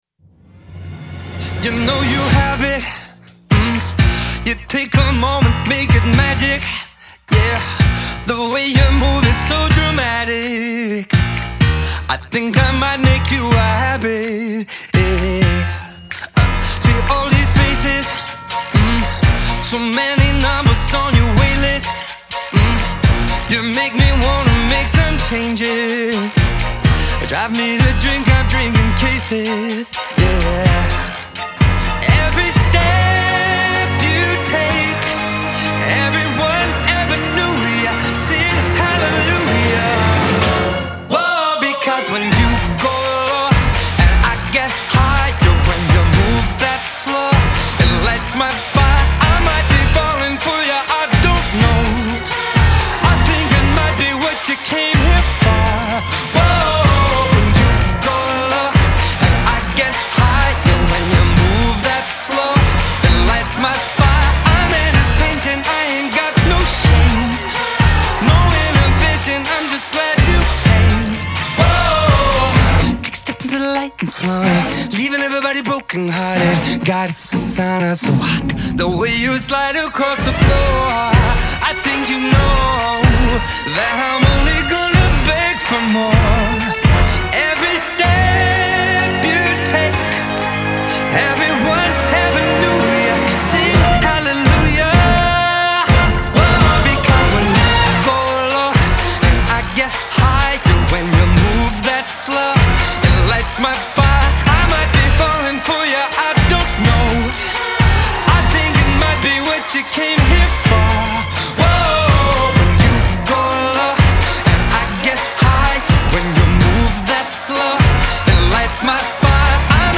Genre : Pop